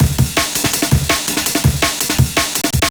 cw_amen08_165.wav